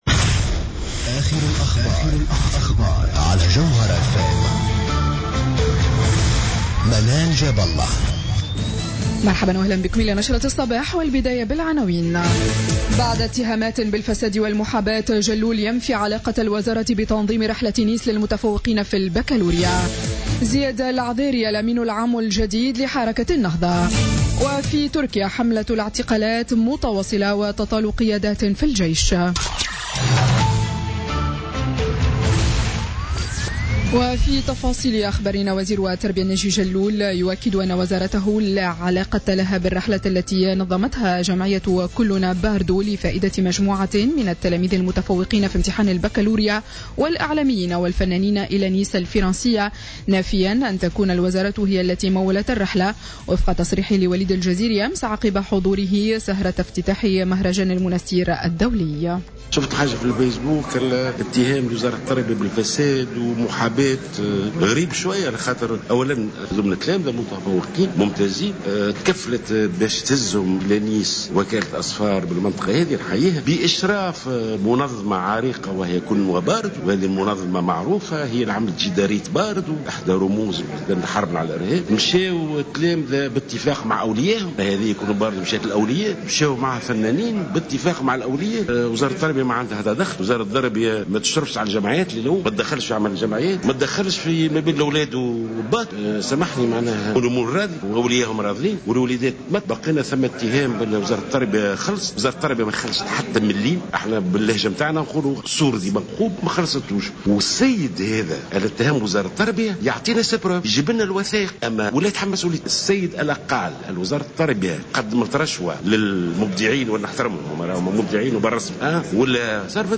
نشرة أخبار السابعة صباحا ليوم الاثنين 18 جويلية 2016